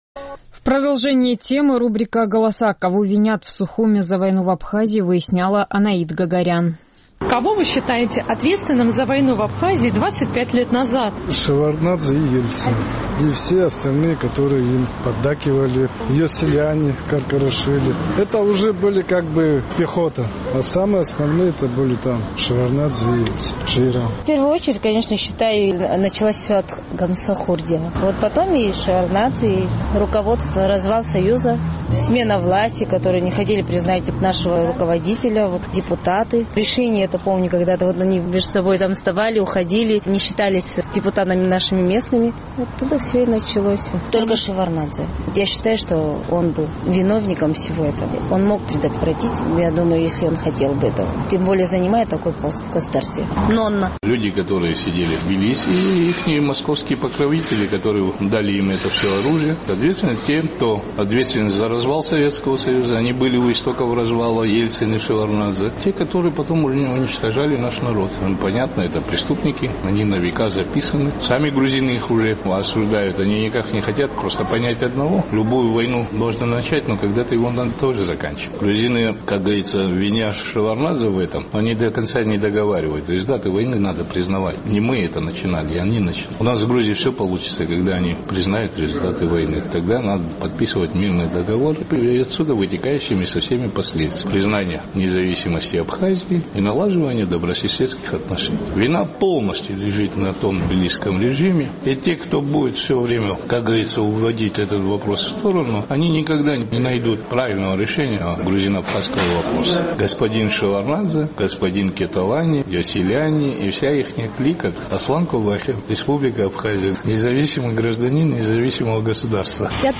Наш сухумский корреспондент поинтересовалась у жителей абхазской столицы, кого они считают ответственным за войну в Абхазии 25 лет назад.